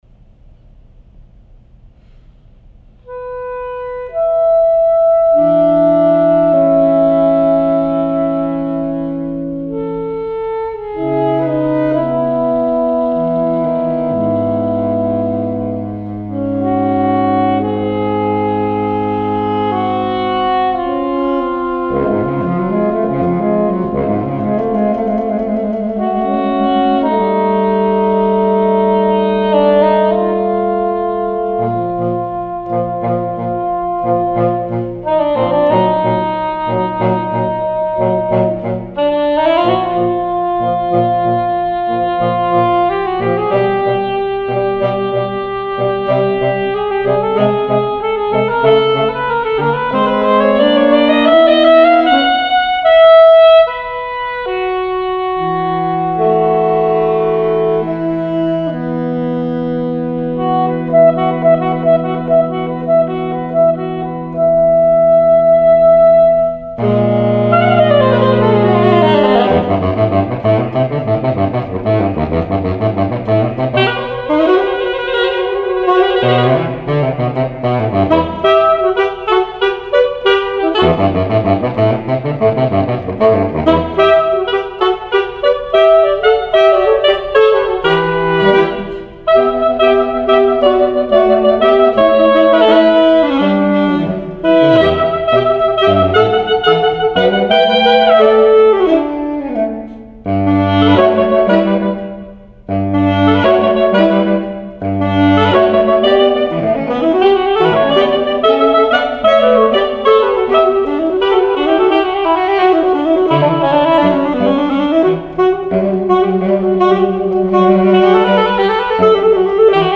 Voicing: Saxophone Trio (AAB)